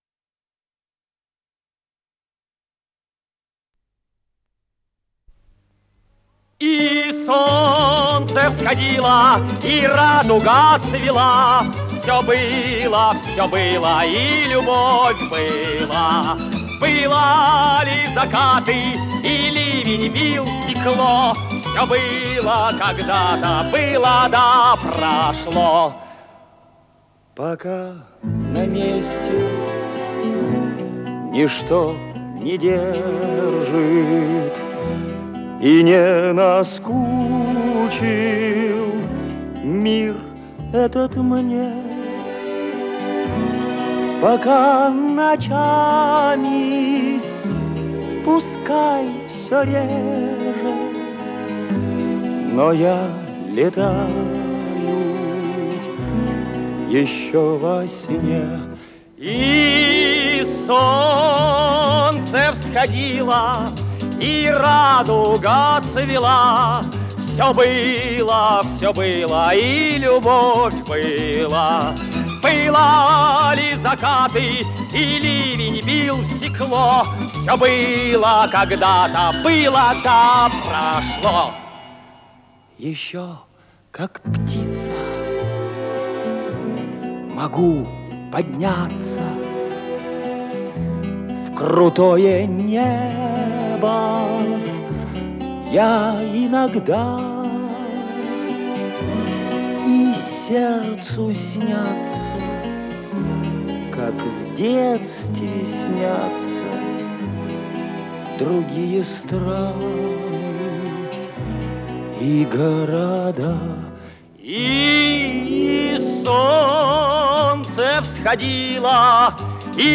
с профессиональной магнитной ленты
Скорость ленты38 см/с